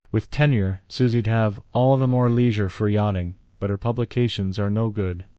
text-to-speech
The Convert Text to Fish Speech action is designed to transform textual content into synthetic speech, leveraging the Fish Speech V1.2 model.
Upon successful processing, the action returns a URI to the generated audio file of the synthesized speech, allowing you to easily integrate it into your application (e.g., "